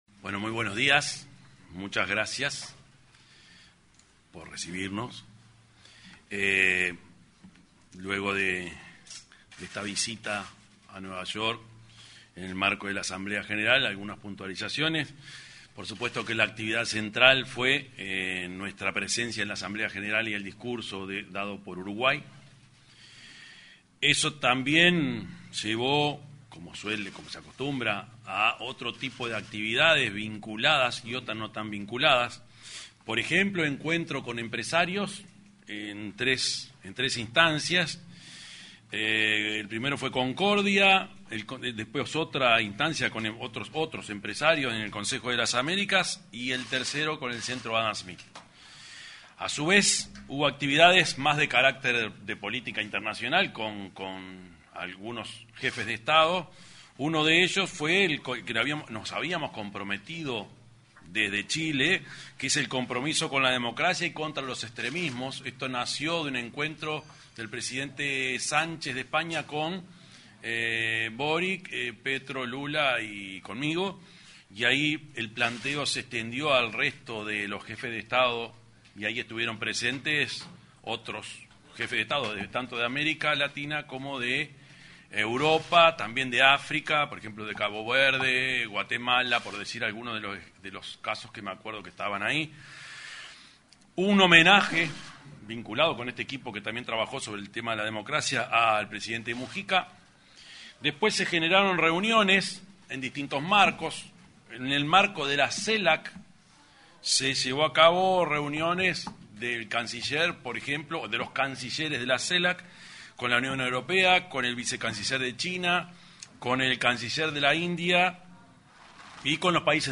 Declaraciones del presidente Yamandú Orsi
El presidente de la República, Yamandú Orsi, realizó declaraciones a la prensa,al arribar al país tras participar en la Asamblea General de Naciones